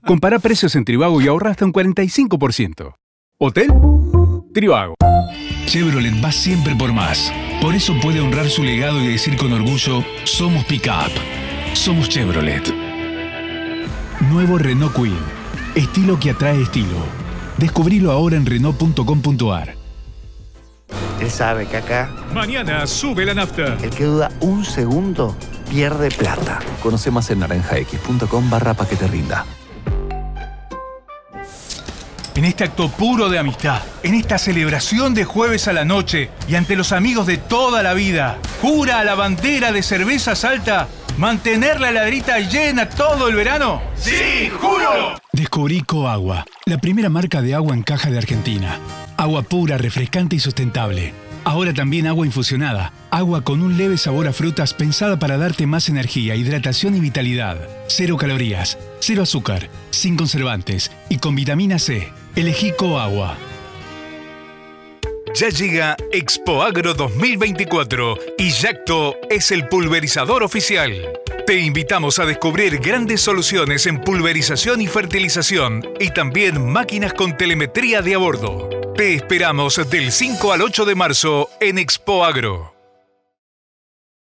Adult male voice, warm and trustworthy, with clear diction and strong on-mic presence.
Spanish Neutral.
Main Demo